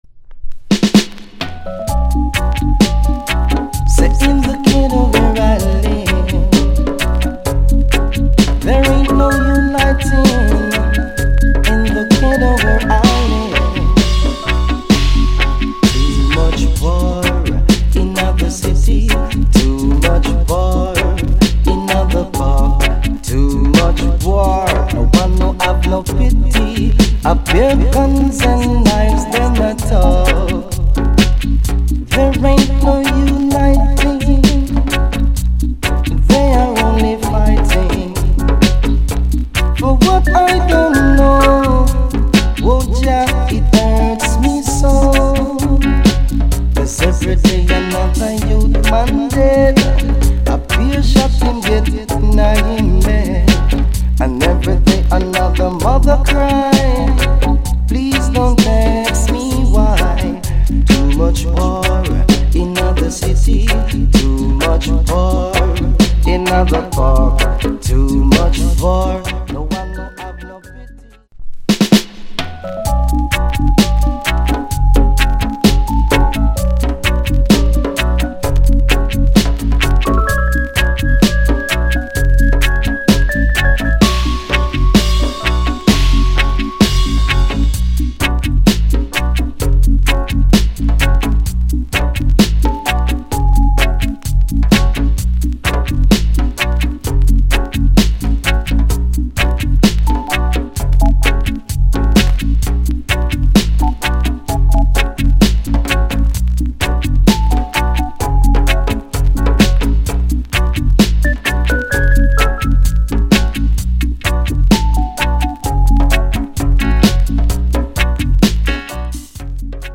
Production UK Genre Roots Rock
Male Vocal
Dubwise Condition EX Soundclip